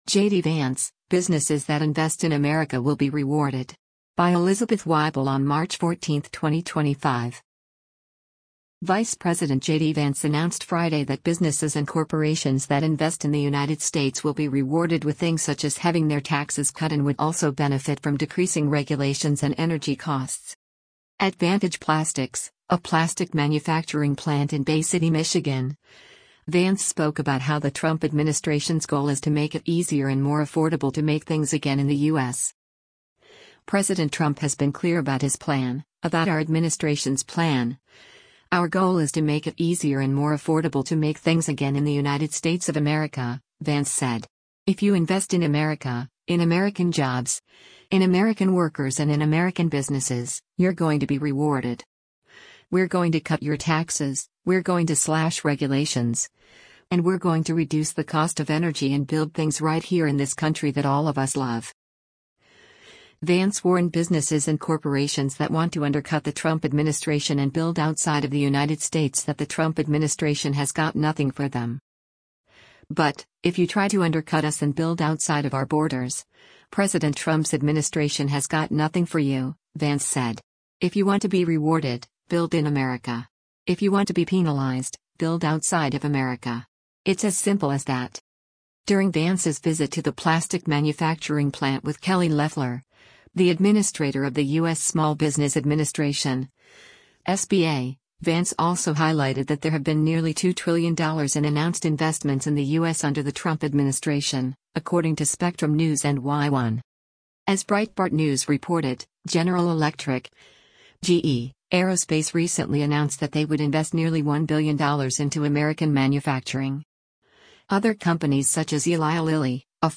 At Vantage Plastics, a plastic manufacturing plant in Bay City, Michigan, Vance spoke about how the Trump administration’s “goal is to make it easier and more affordable to make things again” in the U.S.